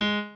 pianoadrib1_10.ogg